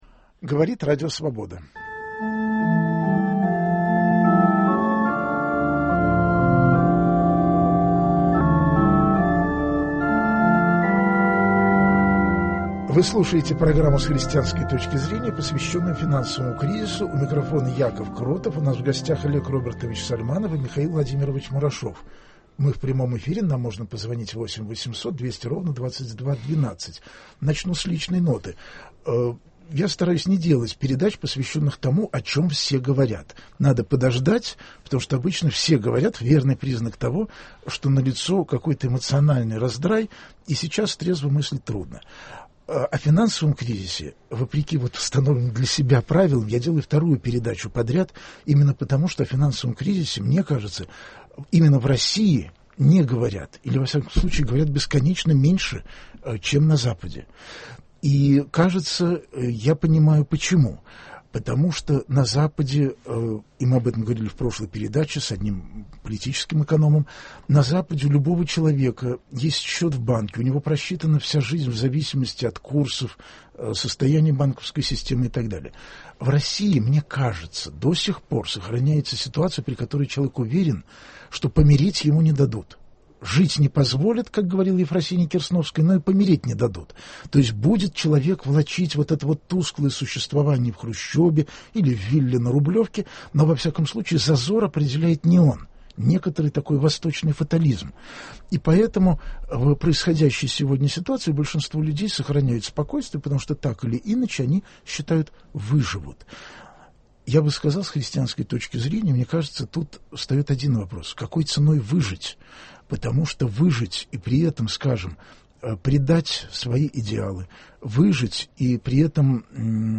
Эфир прямой, звоните прямо нам, только не спрашивайте, когда подешевеет фунт лиха.